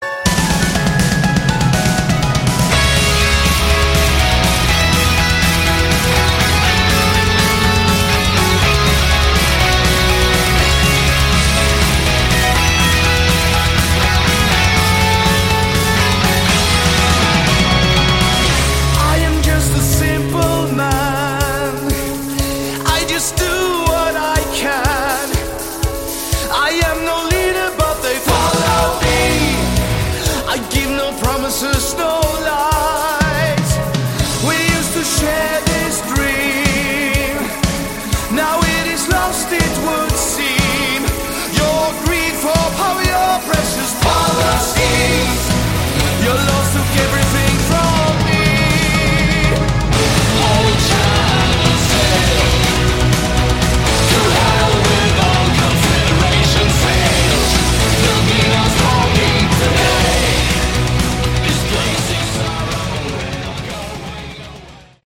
Category: Melodic Rock
synthesizers, vocals
lead vocals, guitar, bass